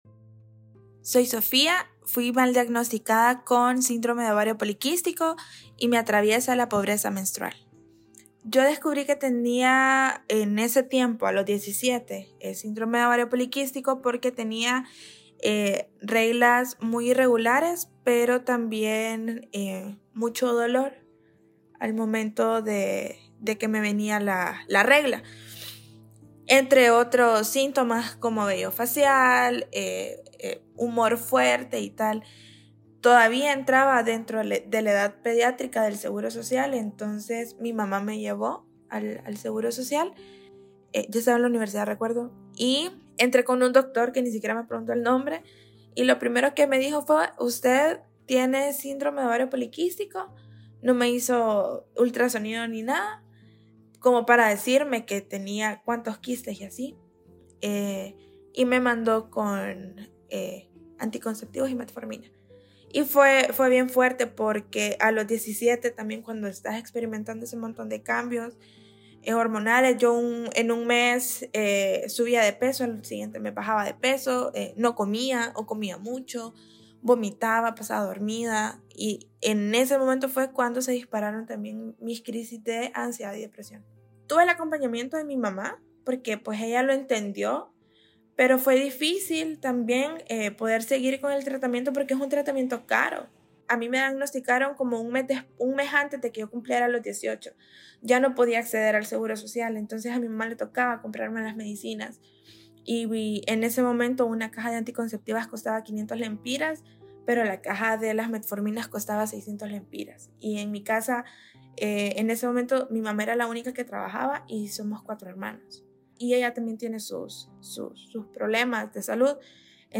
testimonio